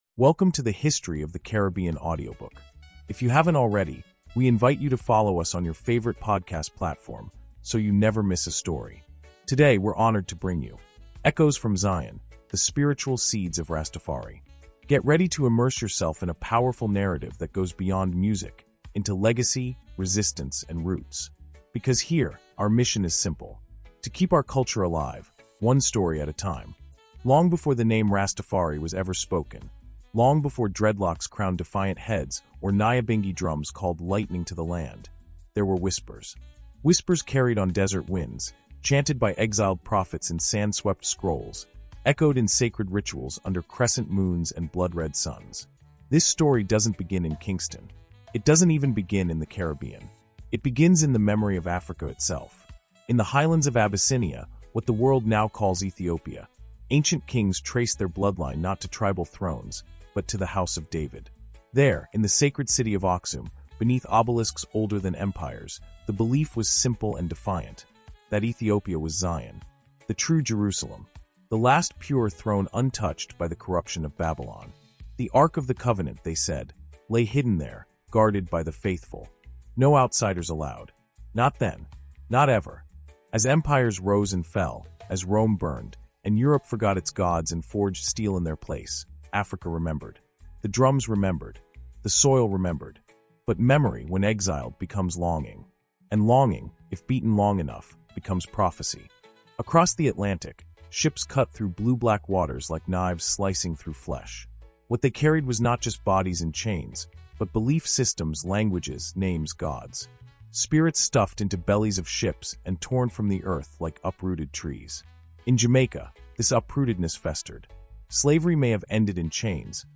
Echoes from Zion – The Spiritual Seeds of Rastafari | Audiobook Insight